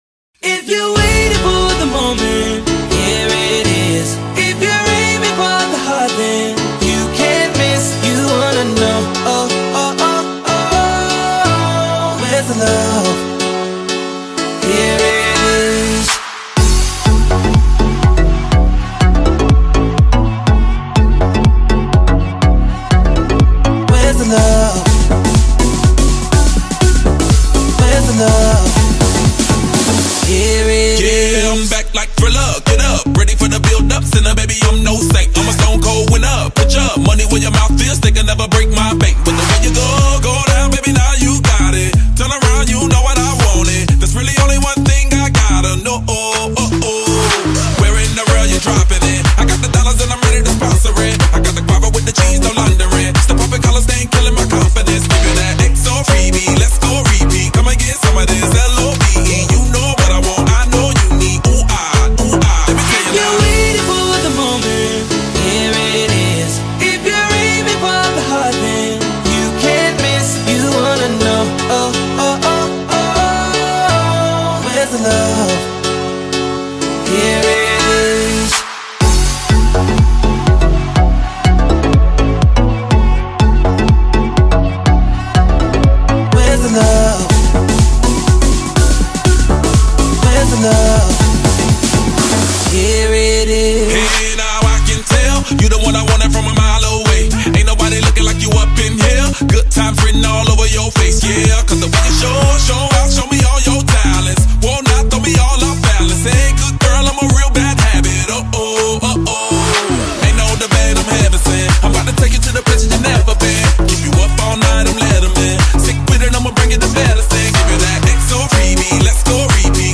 chanteur américain